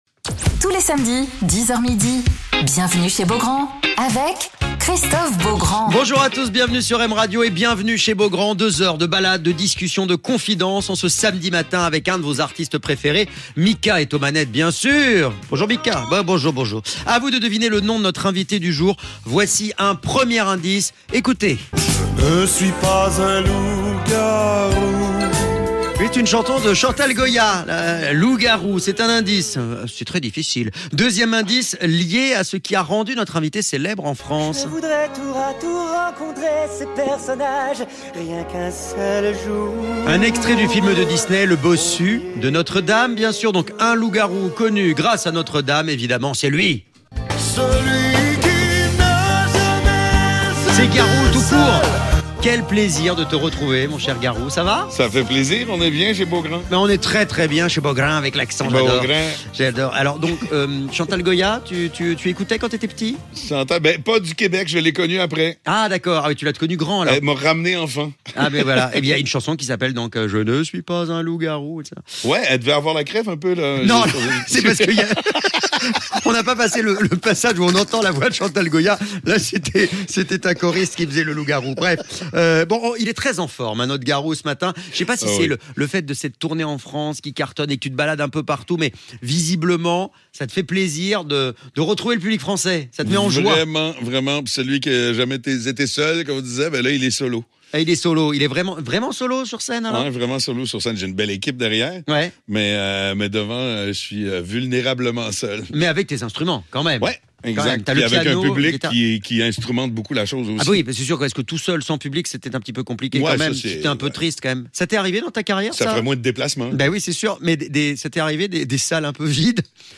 Alors qu'il continue de sillonner la France avec sa nouvelle tournée "Garou Solo", où il présente son dernier album "Un meilleur lendemain", Garou est l'invité de Christophe Beaugrand sur M Radio !